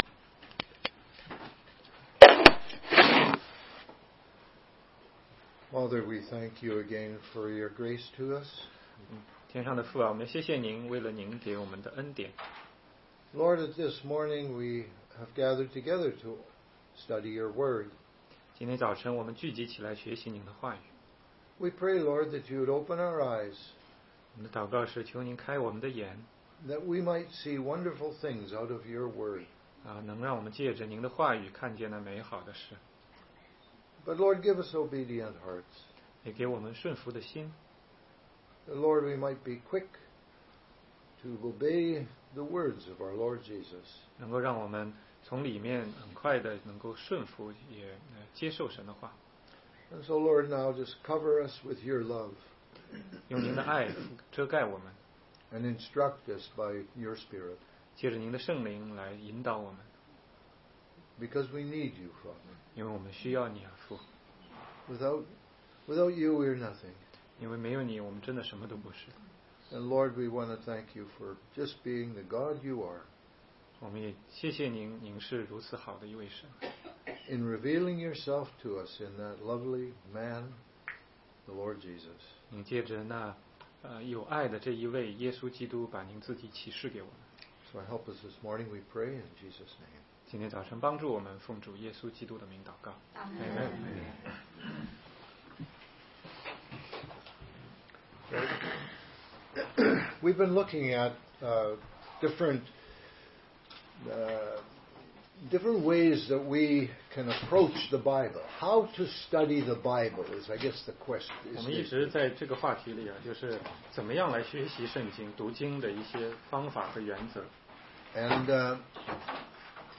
16街讲道录音 - 怎样才能读懂圣经系列之七